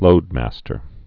(lōdmăstər)